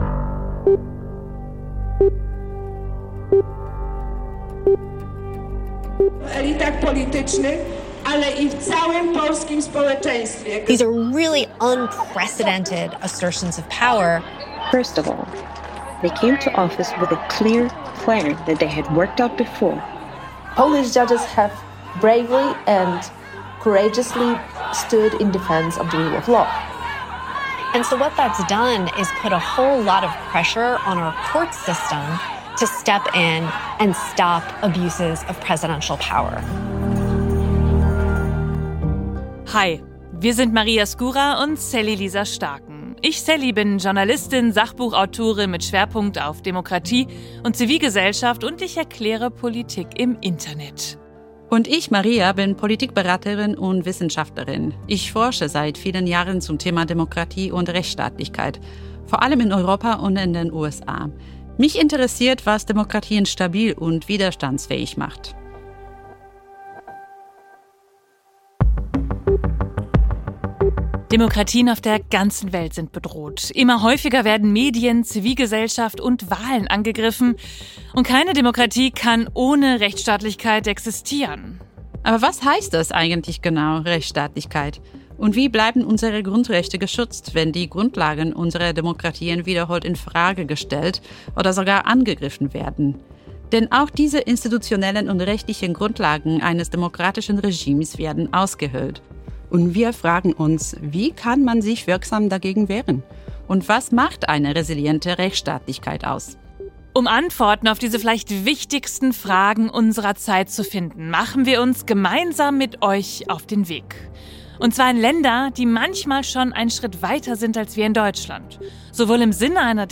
Am Beispiel Polens sowie mit Blick auf Entwicklungen in Israel und den USA sprechen wir mit internationalen Expert:innen darüber, welche gravierenden Folgen der Umbau der Justiz hat und mit welchen Strategien Gerichte, Zivilgesellschaft und internationale Netzwerke von Jurist:innen ihre Unabhängigkeit verteidigen Auch zu hören auf Externer Link: Spotify , Externer Link: Apple und Externer Link: Deezer .